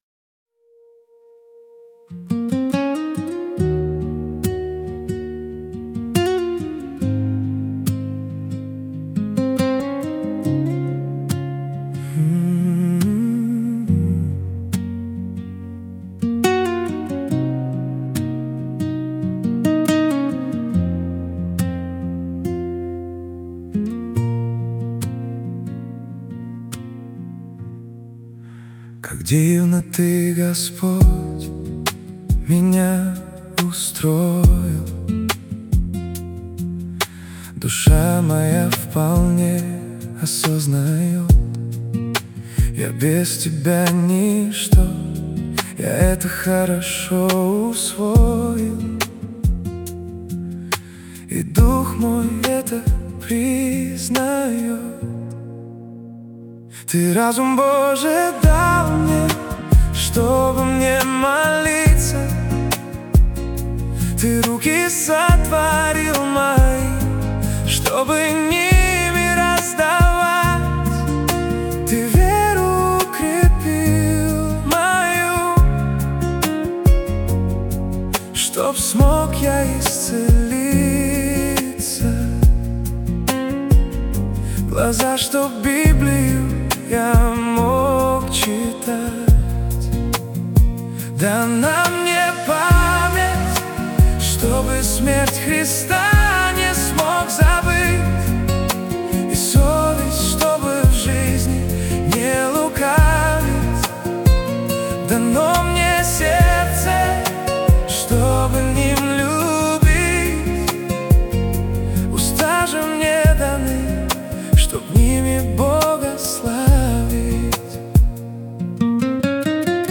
песня ai
8 просмотров 37 прослушиваний 0 скачиваний BPM: 70